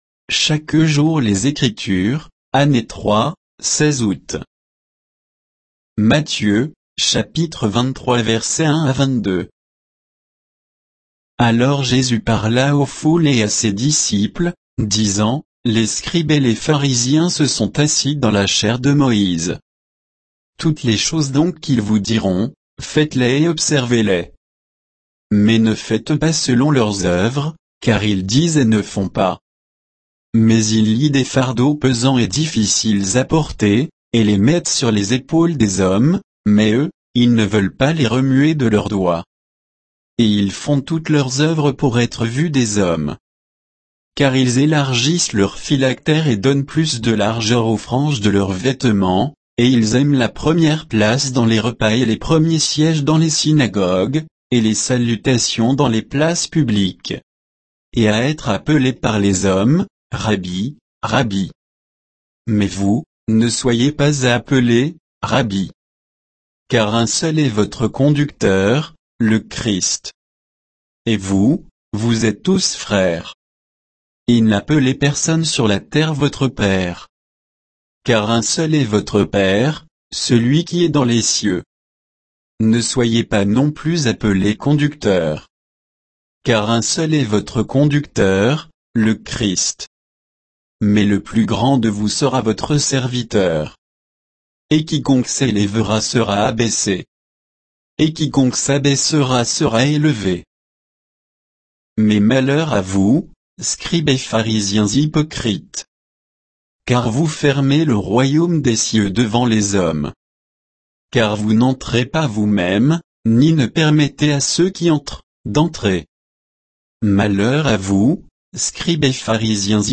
Méditation quoditienne de Chaque jour les Écritures sur Matthieu 23, 1 à 22, par J.Koechlin